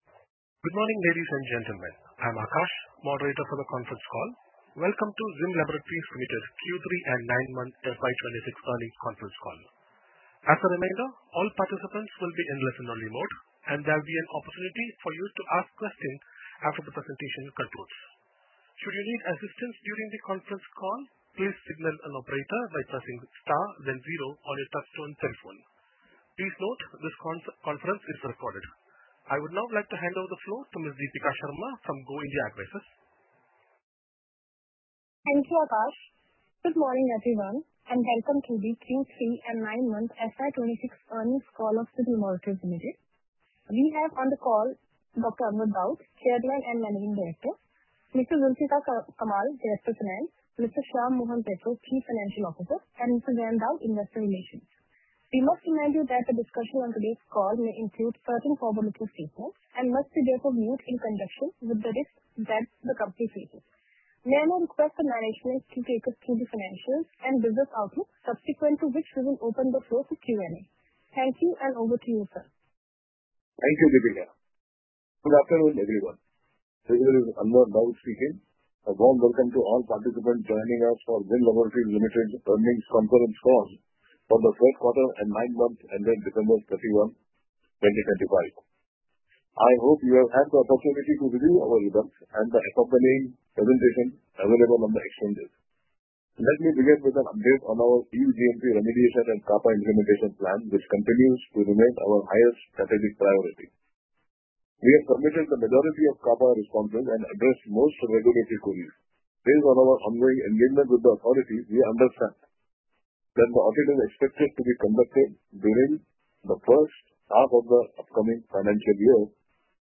Earnings Call Q3 & 9MFY26 - Audio Recording